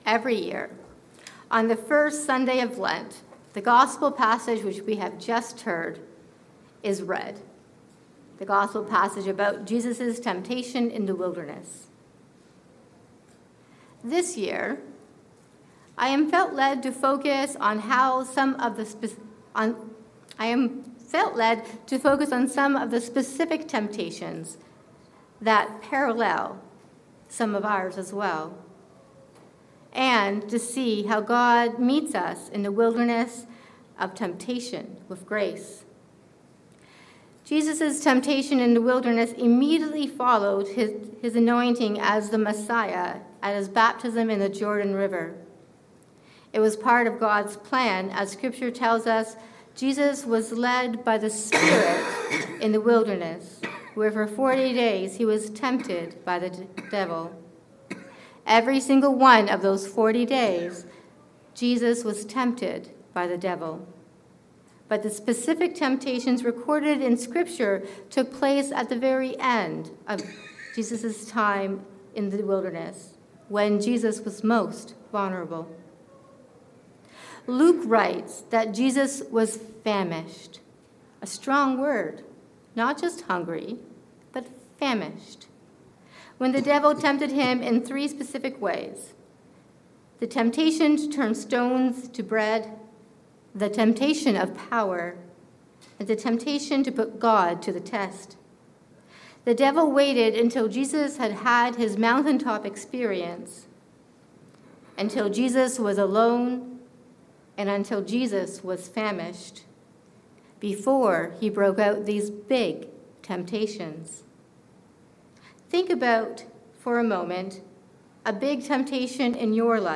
Tempted in the Wilderness. A sermon for the first Sunday in Lent